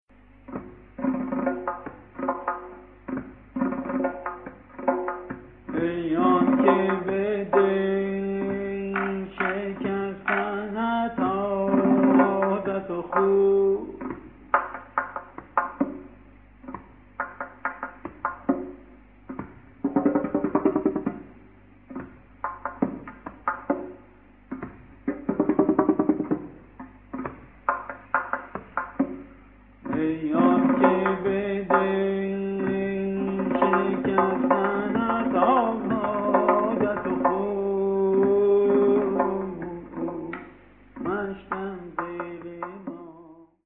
A standard 6/4 groove with variation of a solo tasnif with tombak from the 1970s. Tehrani’s performance is rich with rhythmic variations, groupings, and somehow similar to how tasnif tradition utilizes rhythmic motifs.